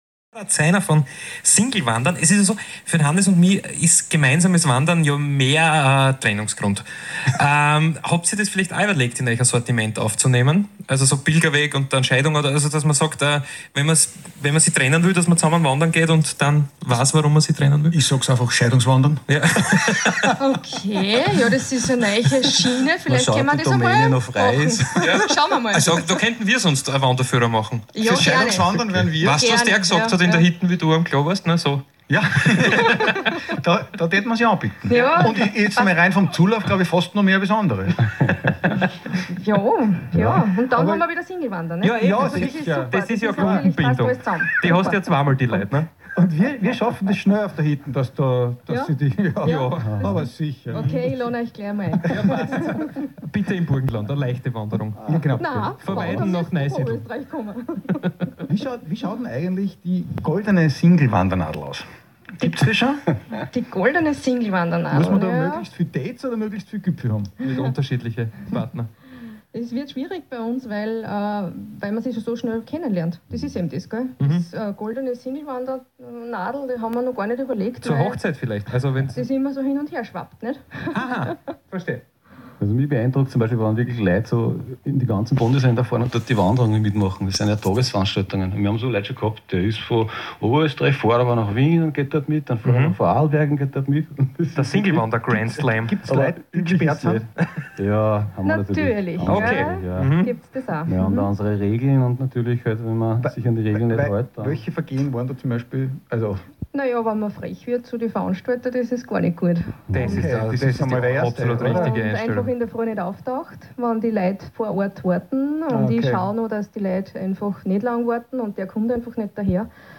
FM4 Interview